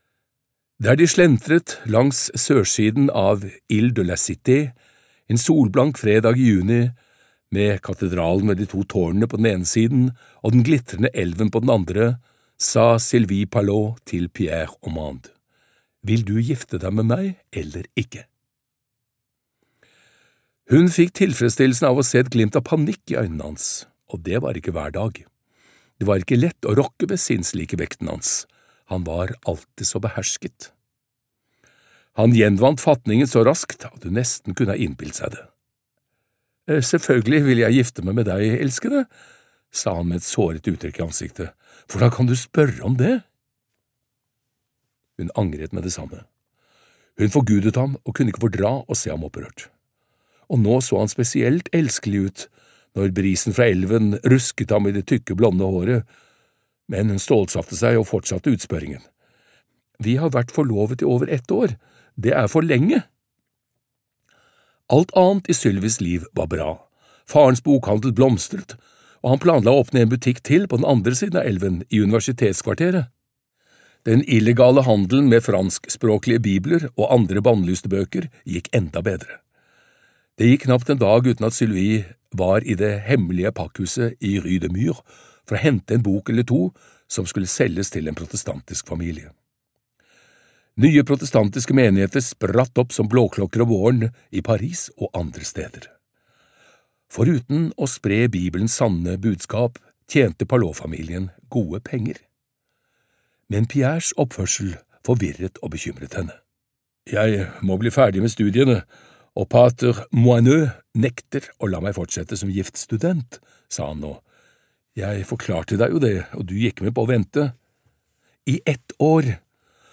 Den evige ilden - Del 5 (lydbok) av Ken Follett